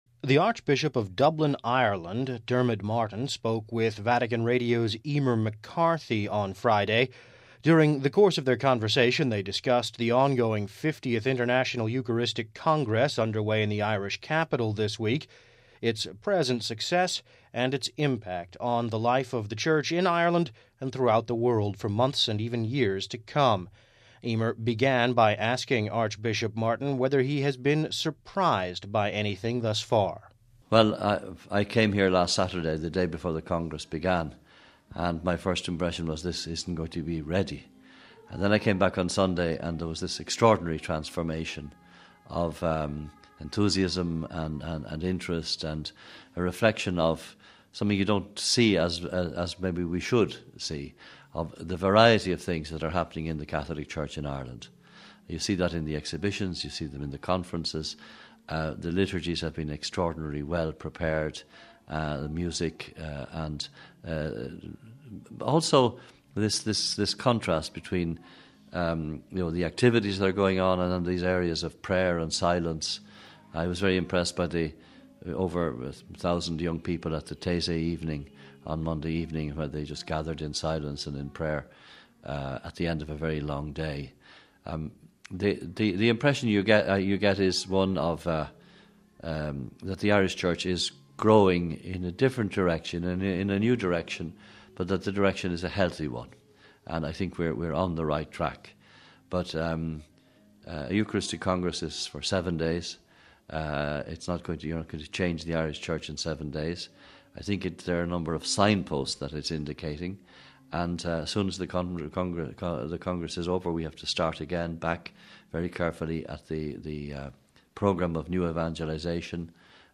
As the International Eucharistic Congress in Dublin heads into its final days, the city's Archbishop, Diarmuid Martin speaks to Vatican Radio in an exclusive interview about how the congress can impact on the future of the Irish Church and his impressions of the event.